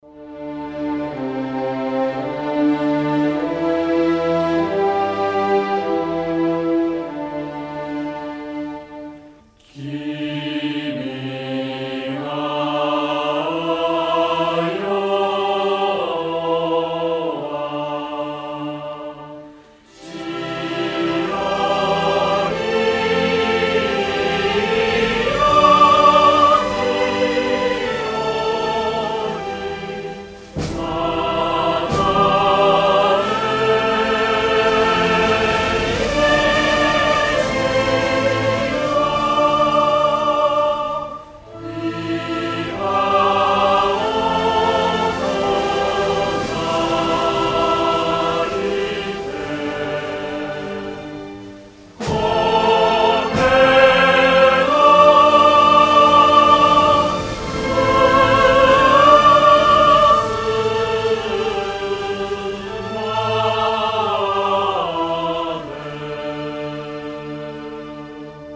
• Качество: 320, Stereo
хор
гимны